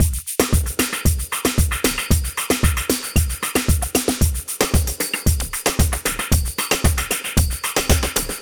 Ala Brzl 2 Drumz 1.wav